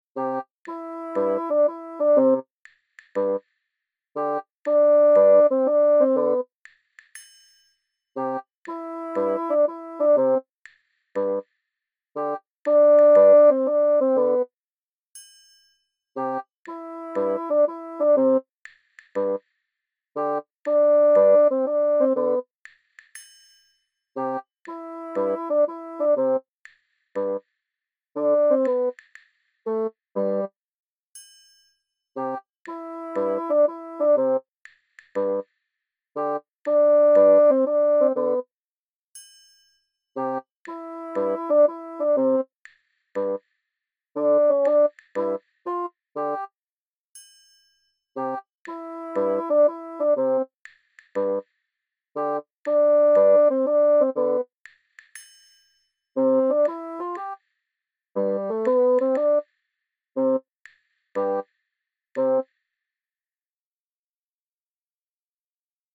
Download Funny Walk sound effect for free.